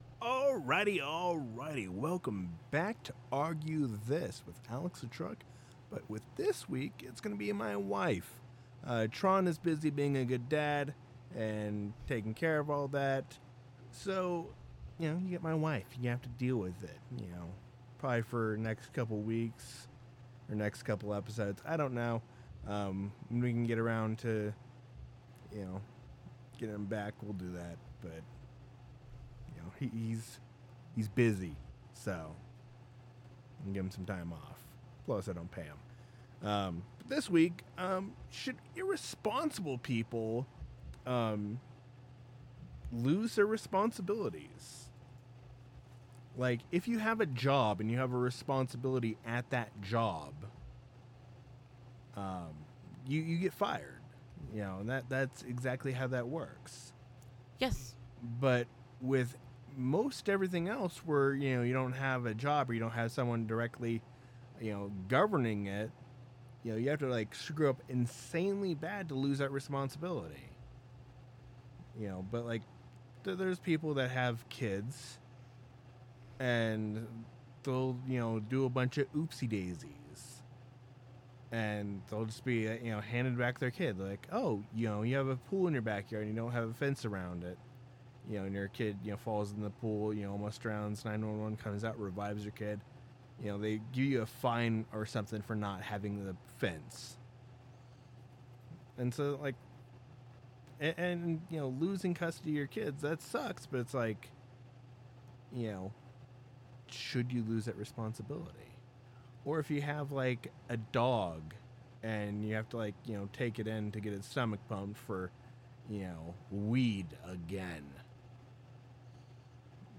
[Explicit Language] You lose your job if you are irresponsible, should you lose other responsibilties if you mess up.